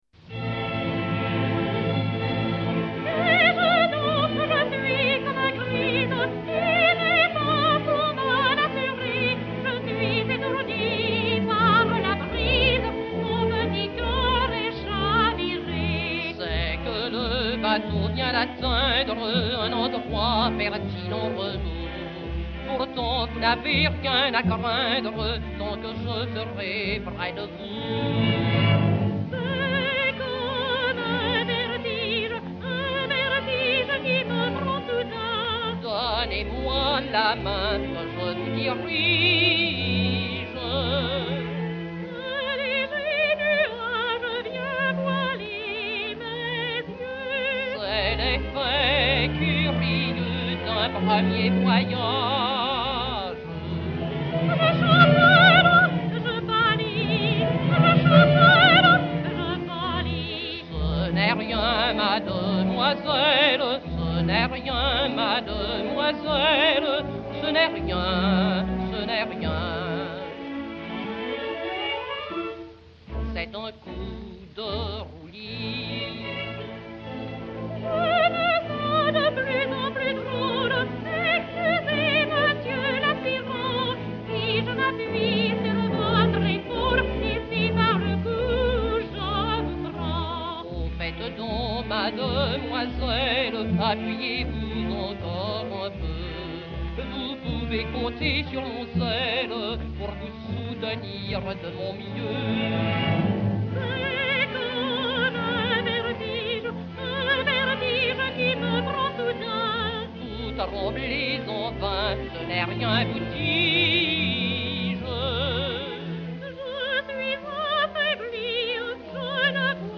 et Orchestre